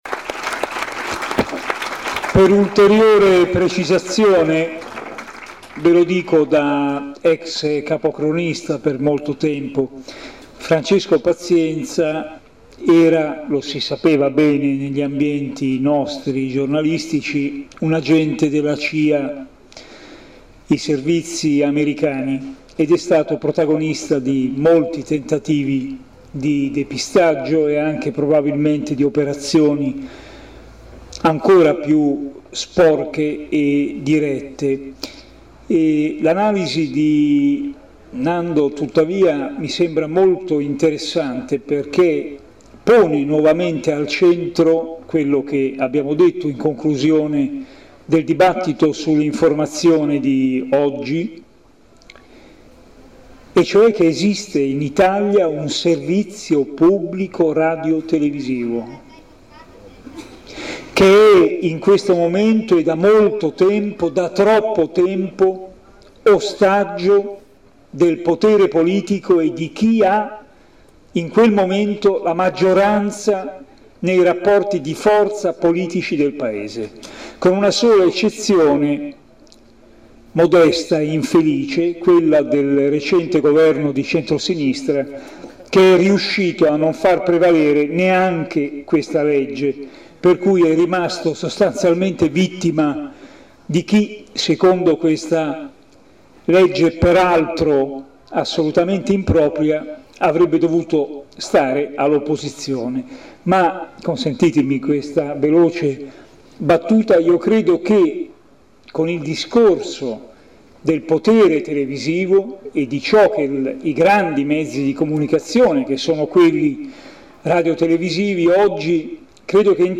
Nell’ambito della “ Quinta Festa di Libera “ con tema “ Incontri e dibattiti per una cultura della legalità “ si è tenuto il 5 luglio 2008 a Savignano sul Panaro: La conferenza dibattito “ Mafia, ma non solo “